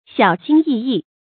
注音：ㄒㄧㄠˇ ㄒㄧㄣ ㄧˋ ㄧˋ
小心翼翼的讀法